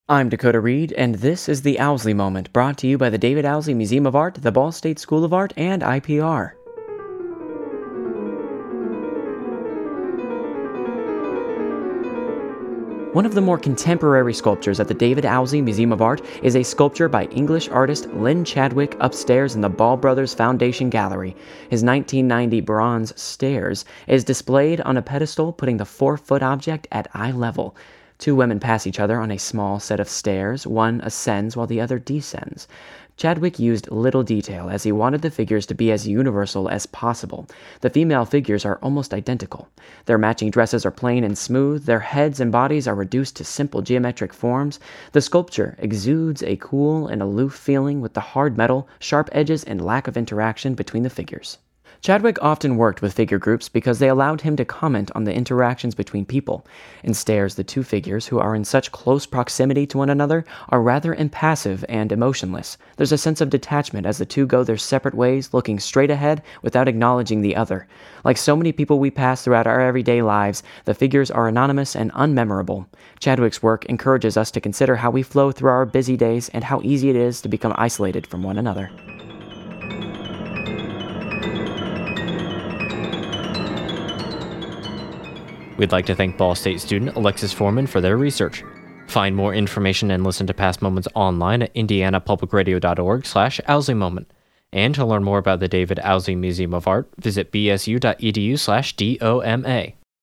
Piano Etudes, Book 2, No. 9: Vertige